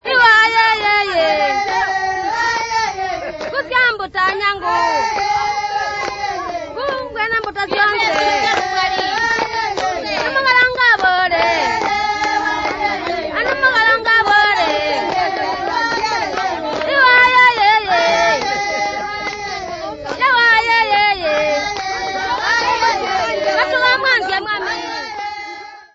Group of Tonga women
Folk songs, Tonga (Zambezi)
Africa Zambia Gwembe f-za
field recordings
The Valley Tonga call it "clapping for rain." clapping being the local method of supplication.
Rain son with clapping.